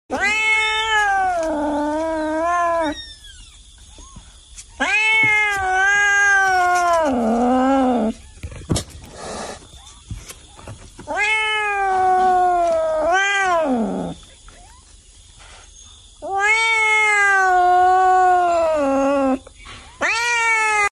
Tiếng mèo kêu meow meow sound effects free download
Tiếng mèo kêu meow meow dùng để đuổi chuột cực kỳ hiệu quả..